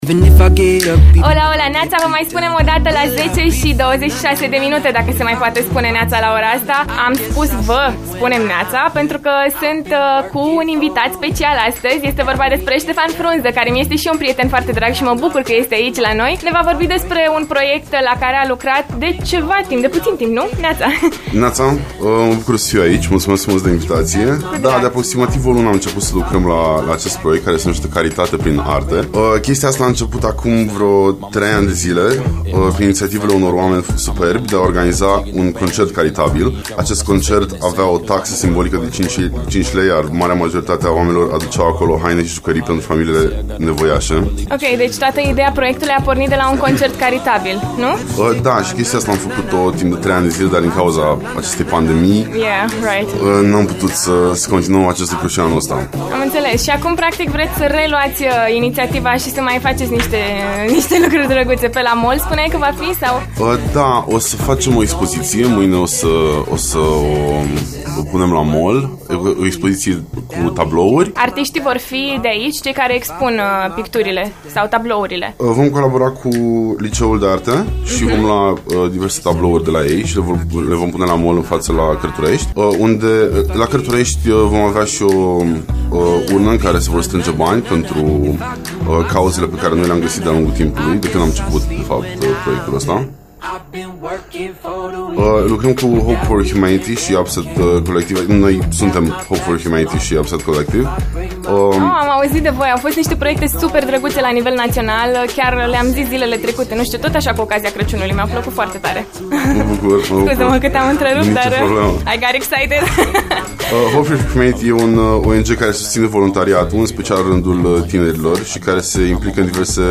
Afli aici, din discuția live